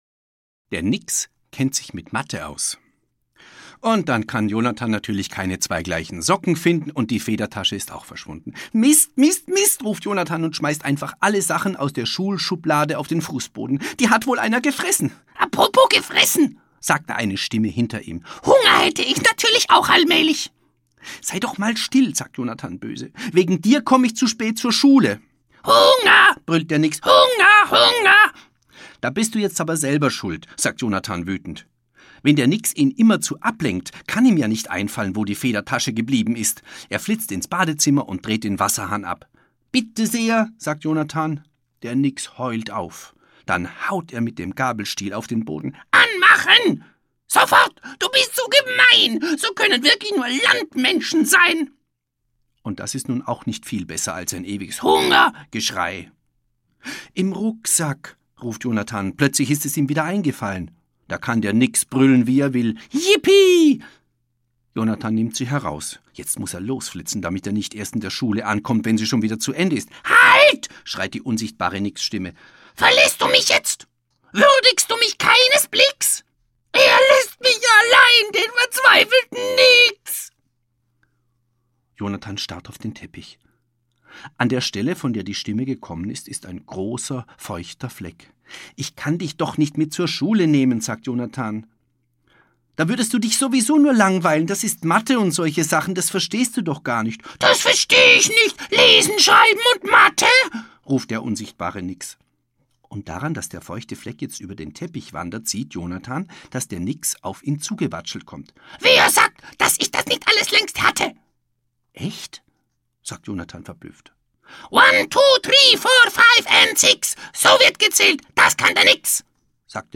Kinder werden ihre große Freude haben, denn der Sprecher ist, wie die musikalische Untermalung, wirklich ganz ausgezeichnet.
Durch wechselnde Stimmlagen lässt er jede Figur sehr lebendig erschienen.